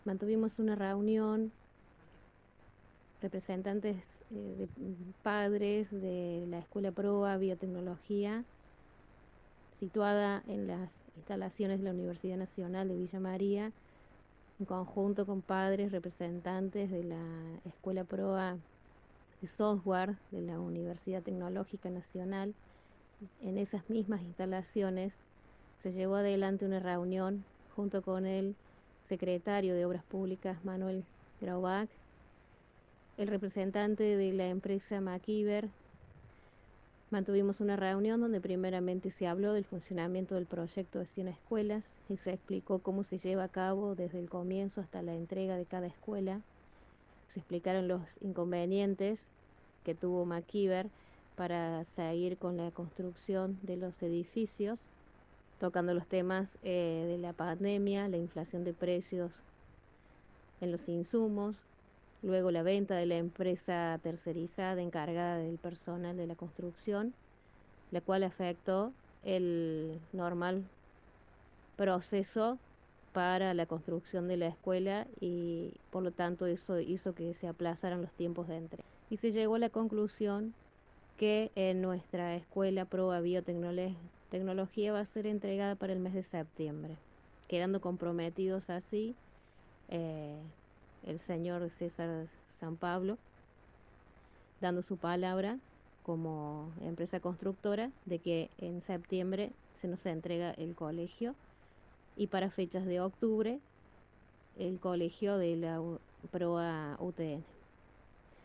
Una de las mamás de los alumnos, se comunicó con nuestro medio y comentó detalles de la reunión: